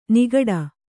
♪ nigaḍa